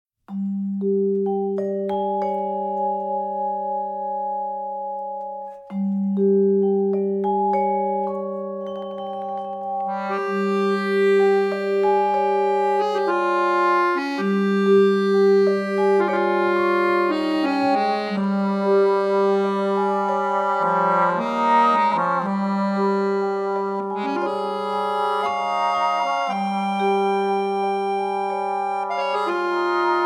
for Trombone, Accordion & Vibraphone